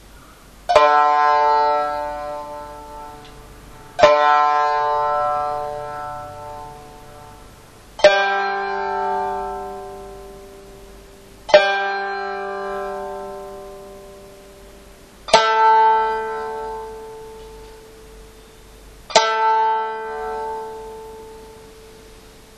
一の音、二の音、三の音の順番に二回ずつ実音が聴こえます。
ゆっくり流れますので、よく聴きながら同じ音に合わせましょう。
六下り
※1 ここではA を442Hz とするピッチで調絃しています。